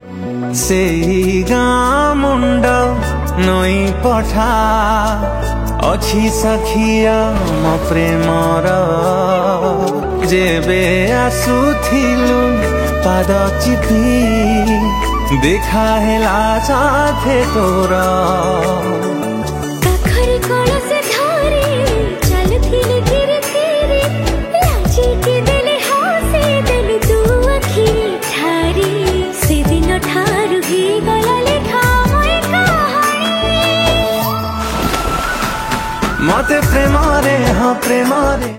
Odia Album Ringtones